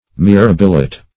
\Mi*rab"i*lite\